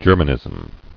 [Ger·man·ism]